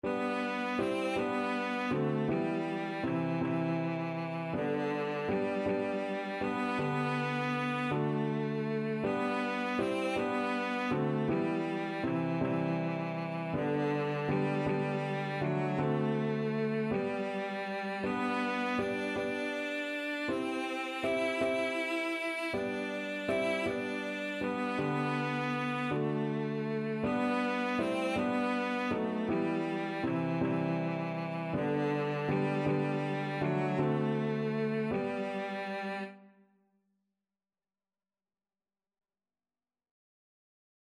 6/4 (View more 6/4 Music)
Classical (View more Classical Cello Music)